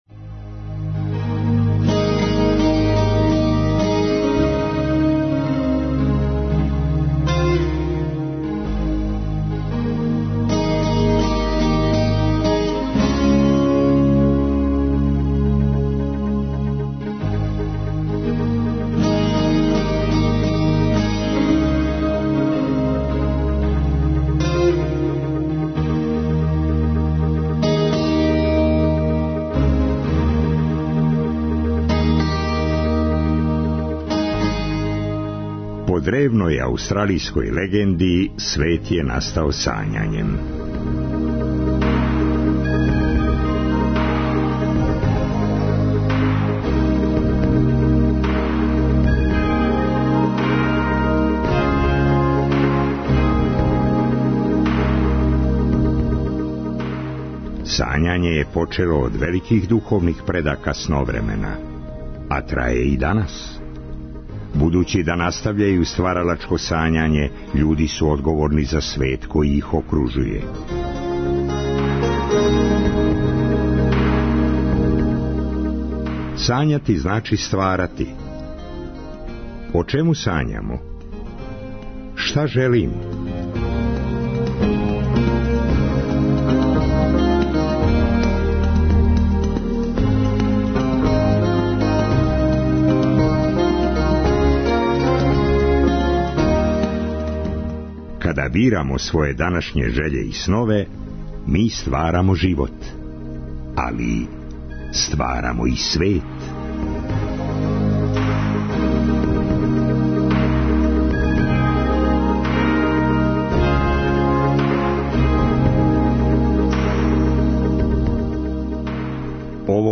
Песници, писци, глумци, музичари... Студентски културни центар Крагујевац.